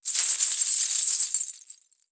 Danza árabe, bailarina mueve las pulseras al bailar 04
agitar
moneda
Sonidos: Acciones humanas